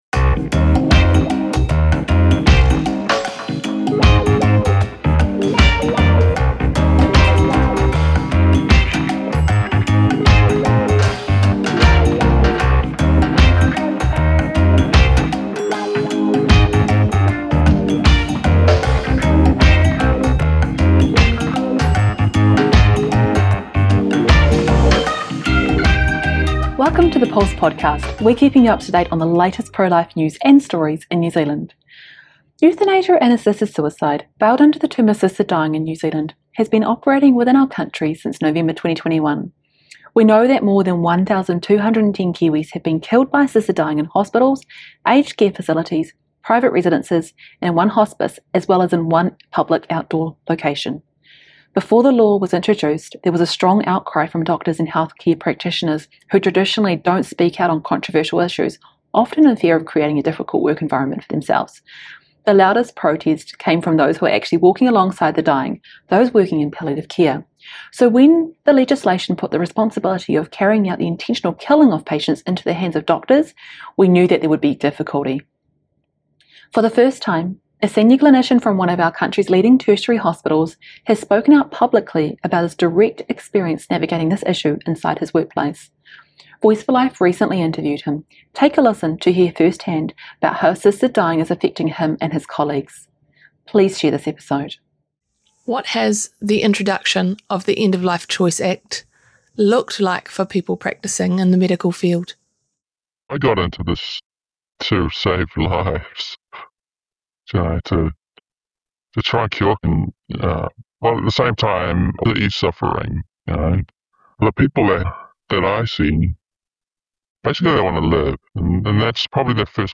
Interview With a NZ Whistleblower Doctor!